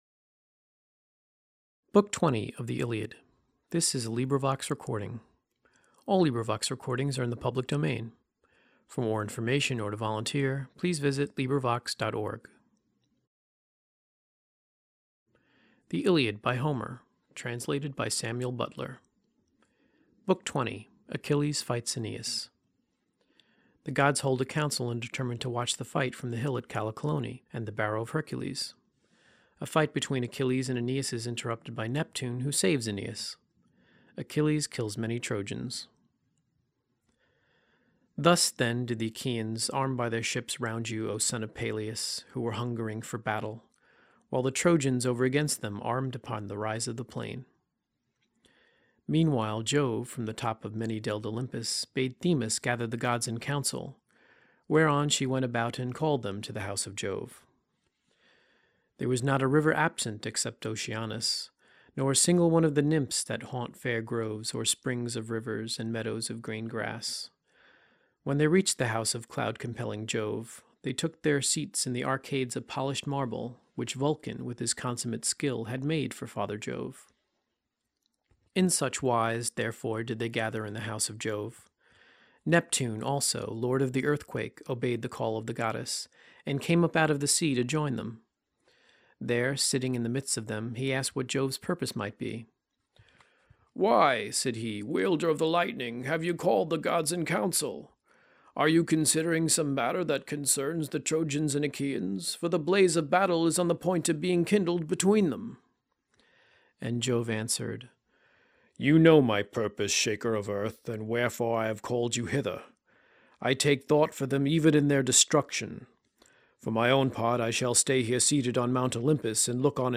LibriVox recording by volunteers. Achilles fights Aeneas.